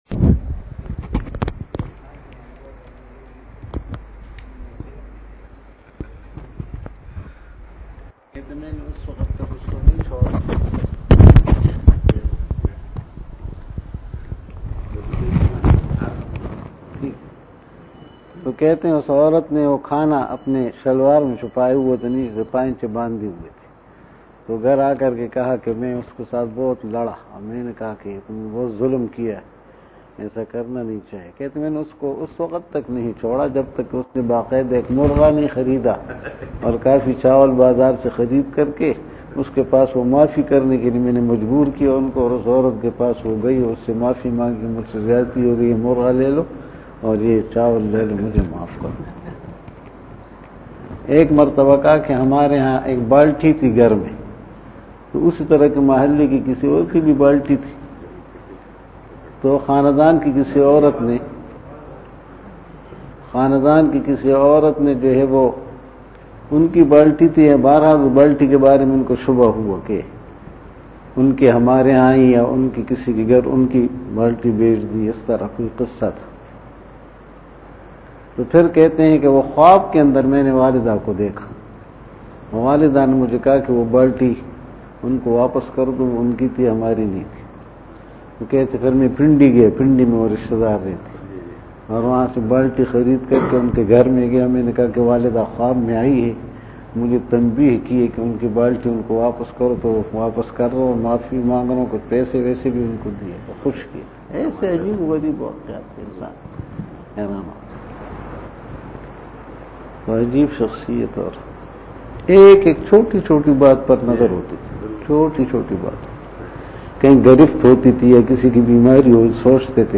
Taziyati bayan